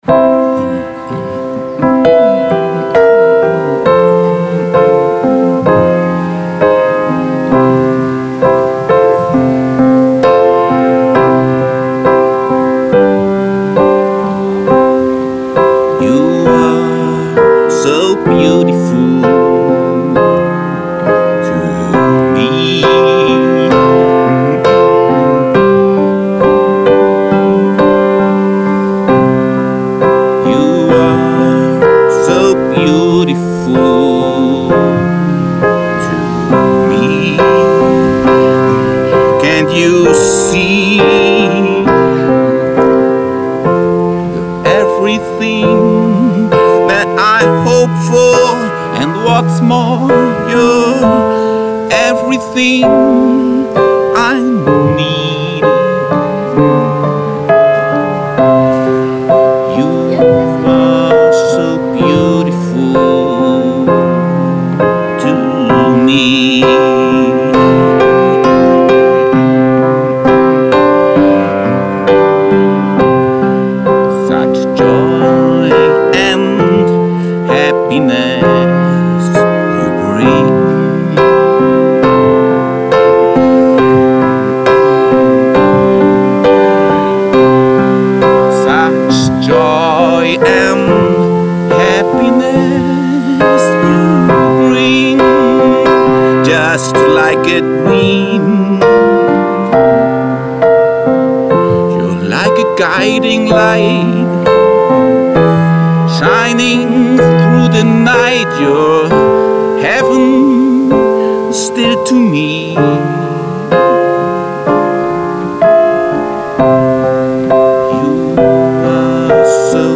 Waldpflanzengarten: